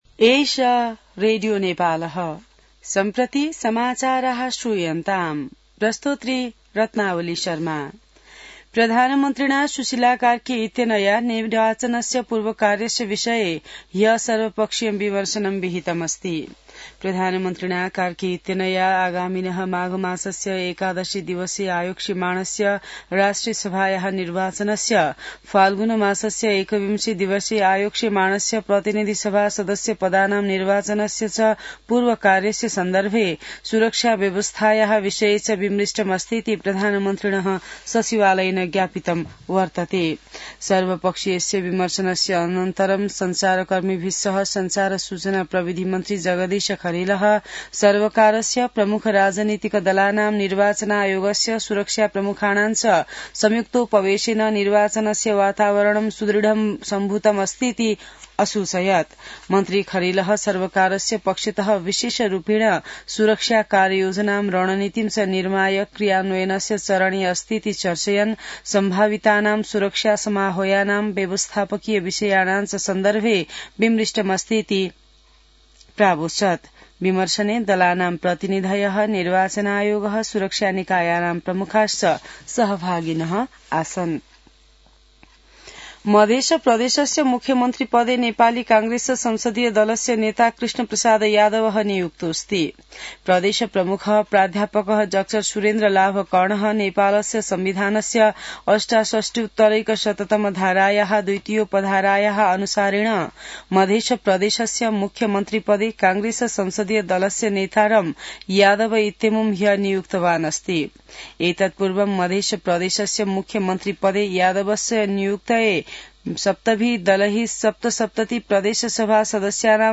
संस्कृत समाचार : २० मंसिर , २०८२